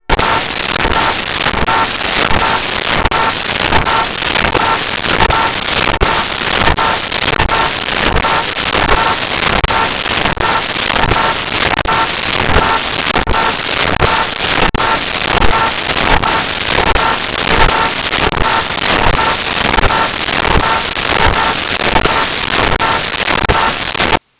Car Horn Repeat
Car Horn Repeat.wav